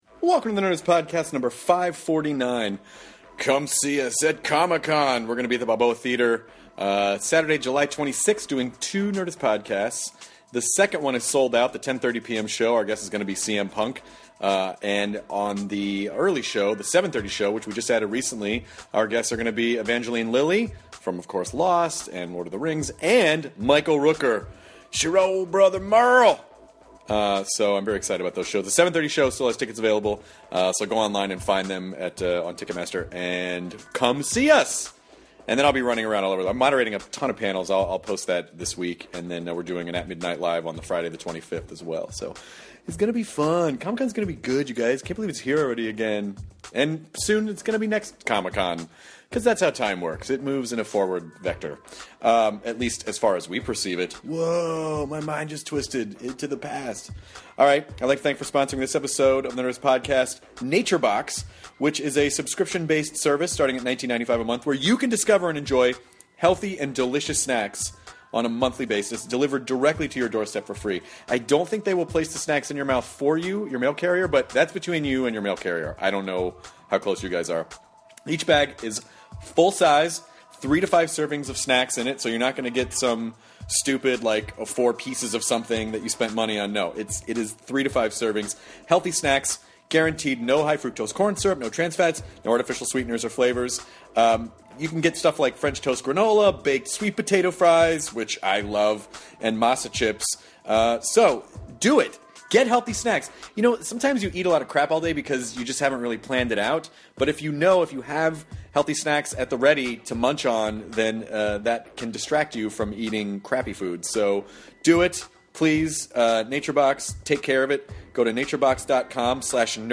Comedian Guy Branum and Chris talk about how excited their 15 year old selves would be about their current lives, how he decided to become a comedian, his solace in being able to be open about his sexuality and the importance of doing the things that make you happy!